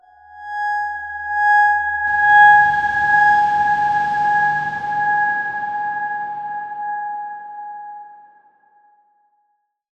X_Darkswarm-G#5-f.wav